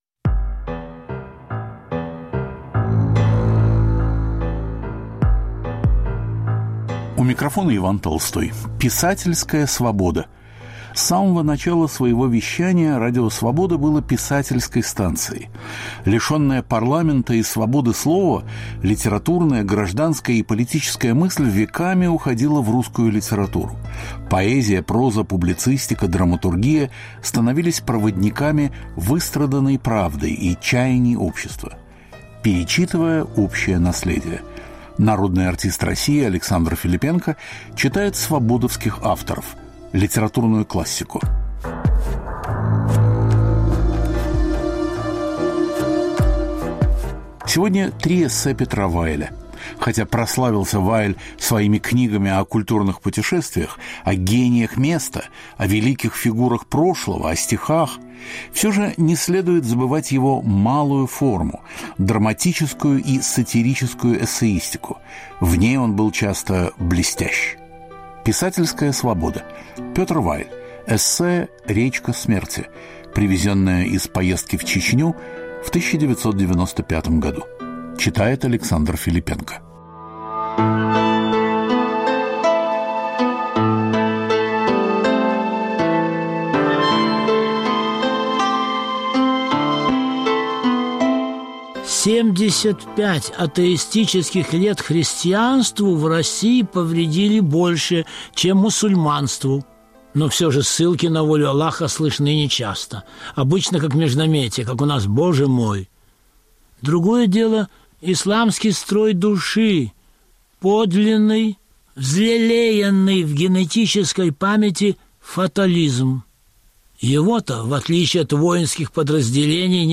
Три очерка Петра Вайля в чтении народного артиста России А. Филиппенко.